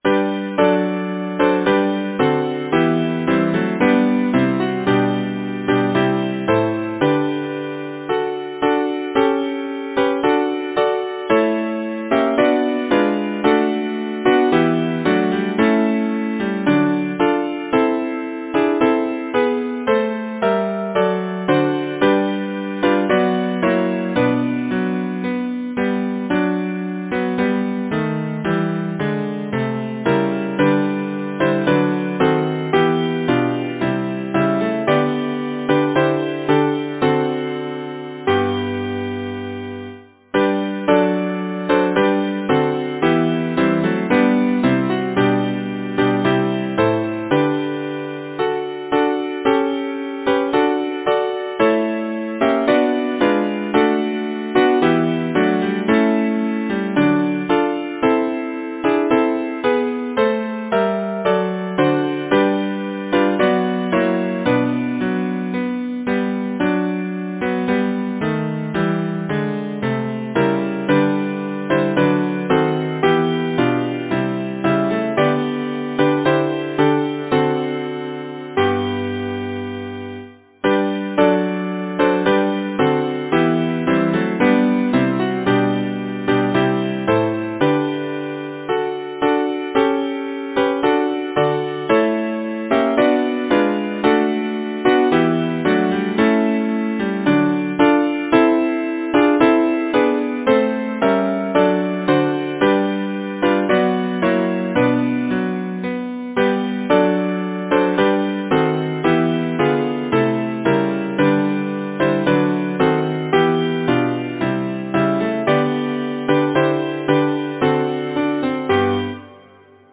Title: Happy by-gone days Composer: Alexander S. Cooper Lyricist: George John Bennett Number of voices: 4vv Voicing: SATB Genre: Secular, Partsong
Language: English Instruments: A cappella